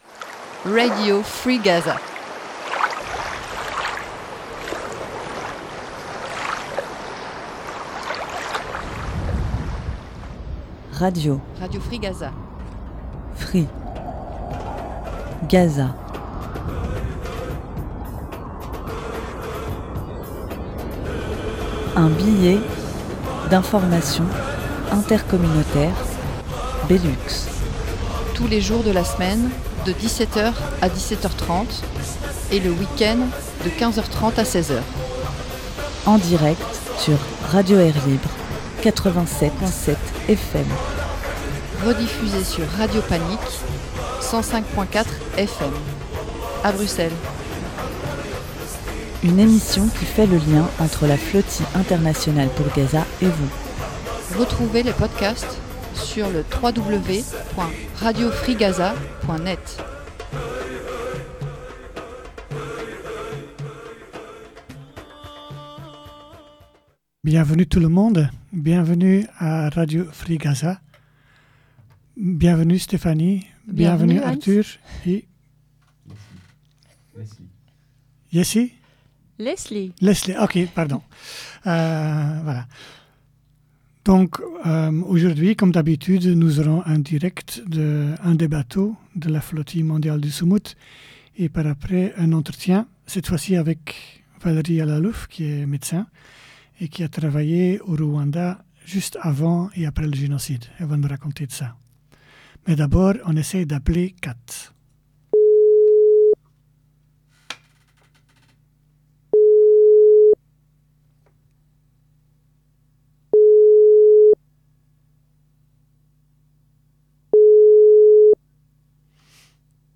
Le direct avec une participante de la délégation belge n’a pas marché.
Un entretien